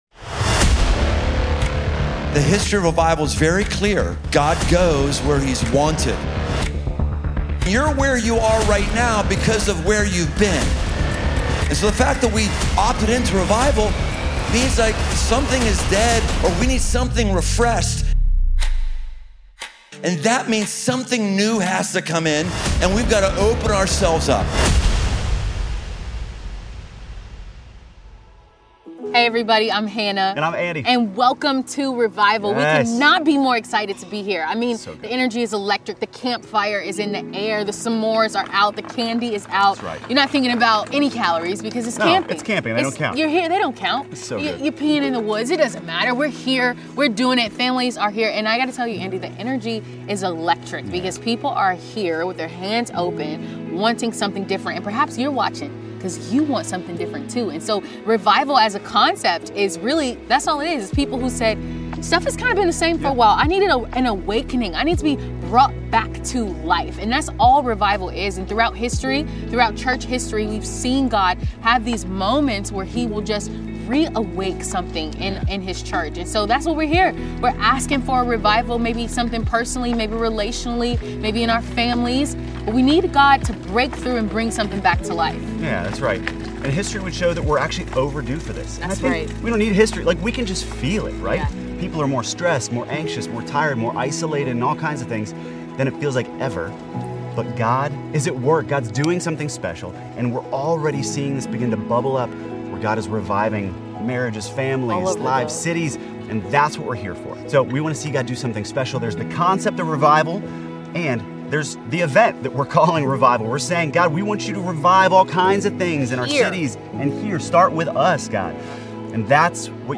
Recorded live at Crossroads Base Camp in Felicity, Ohio.